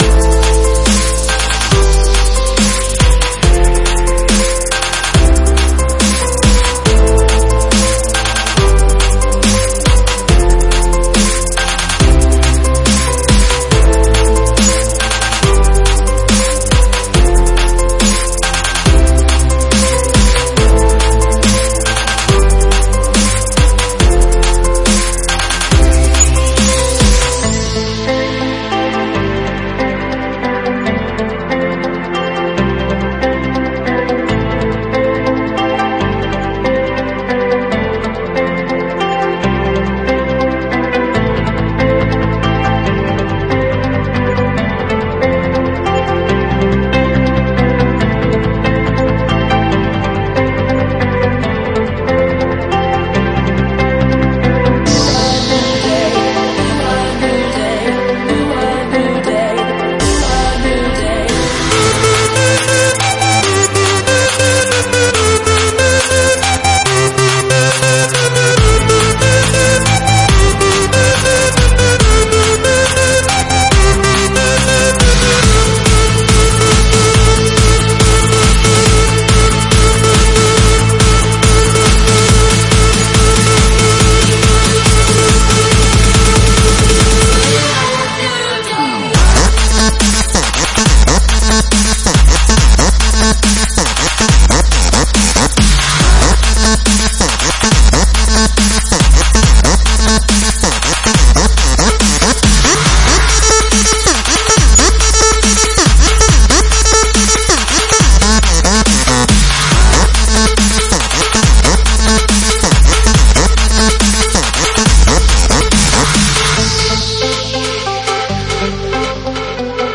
Studio ( Italy )